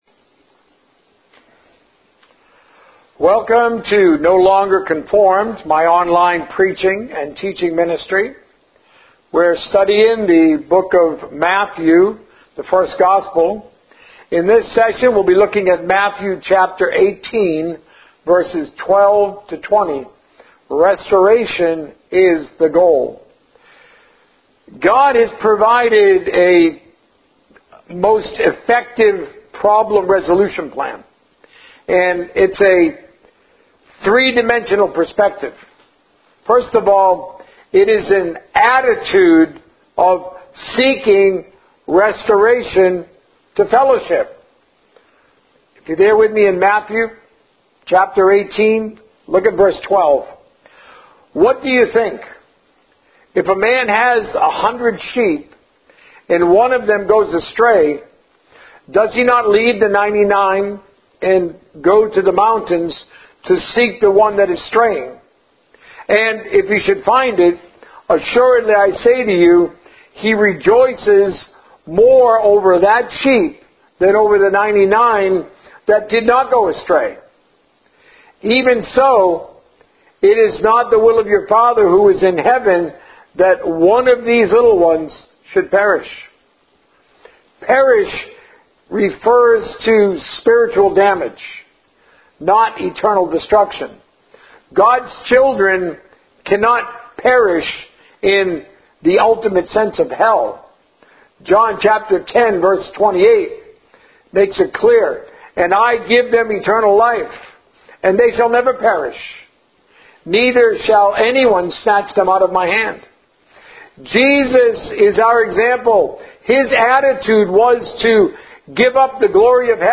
A message from the series "The First Gospel." A Great Confession, A Firm Foundation